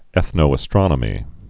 (ĕthnō-ə-strŏnə-mē)